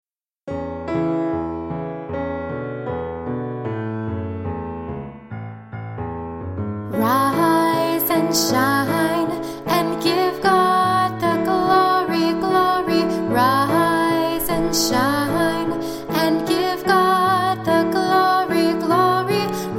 Traditional Song Lyrics and Sound Clip